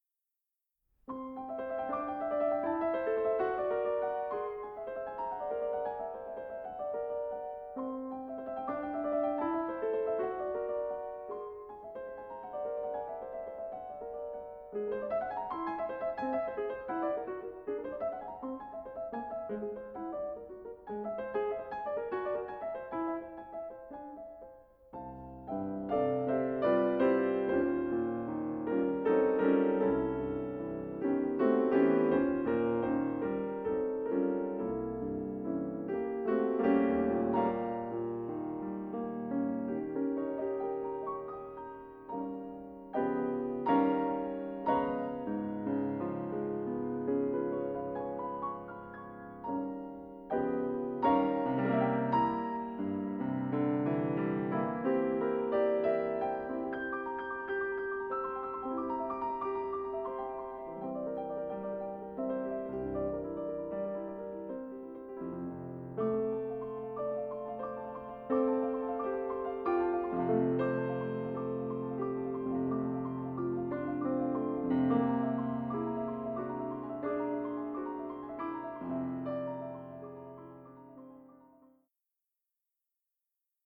piano
these solo piano works, both charming and imposing